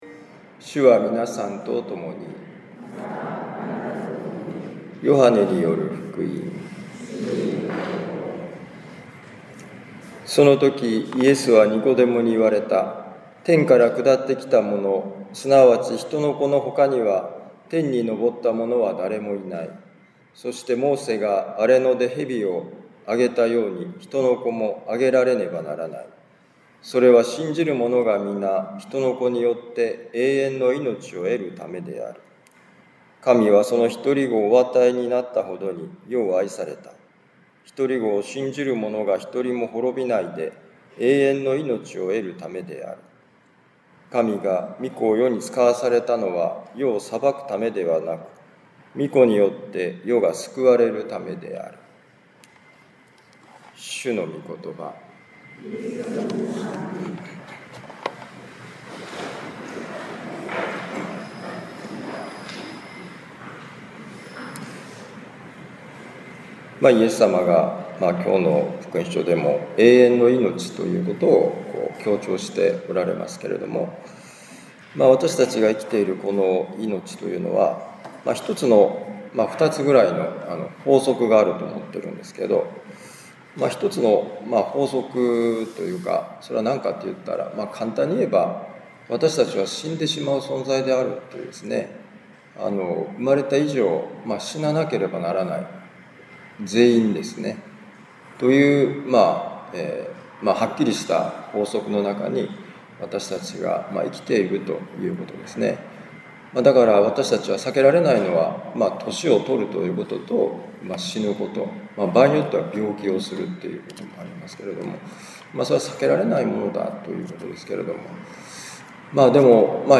【ミサ説教】